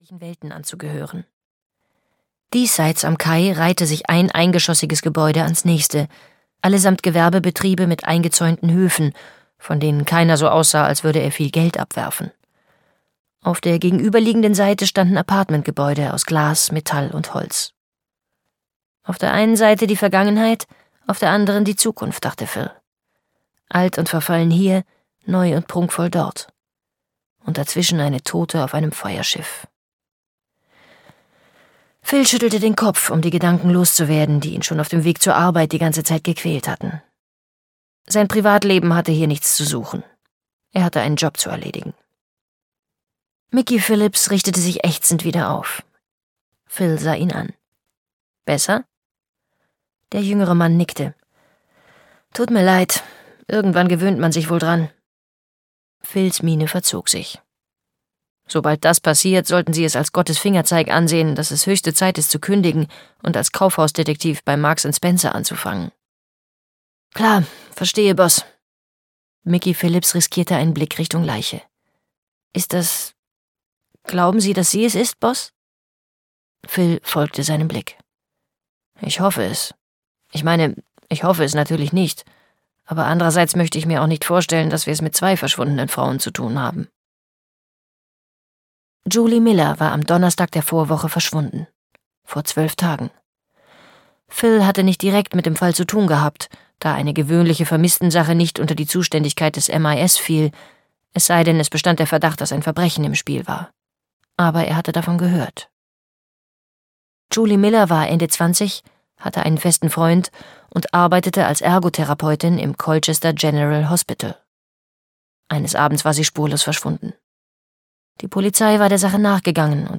Der Stalker (Ein Marina-Esposito-Thriller 2) - Tania Carver - Hörbuch